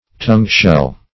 Tongue-shell \Tongue"-shell`\, n. Any species of Lingula.